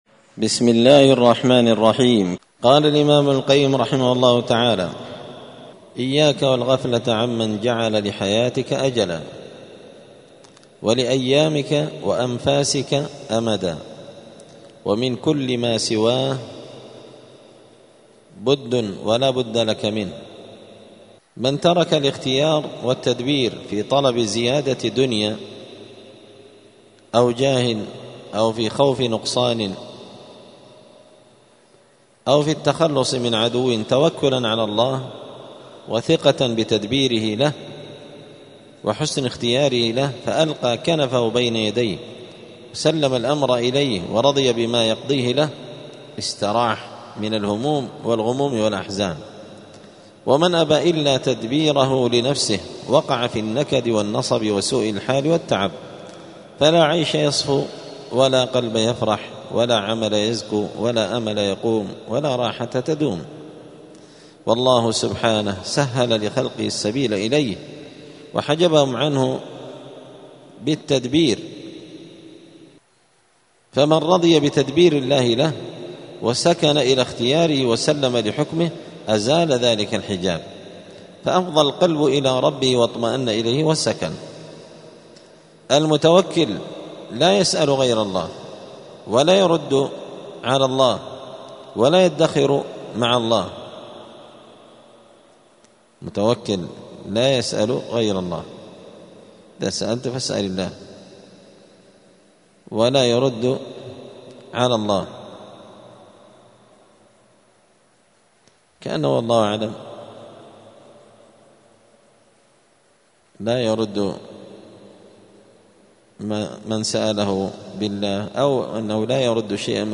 *الدرس الخامس والخمسون (55) {فصل: إياك والغفلة عن من جعل في الحياة أجلا}*
55الدرس-الخامس-والخمسون-من-كتاب-الفوائد-للإمام-ابن-القيم-رحمه-الله.mp3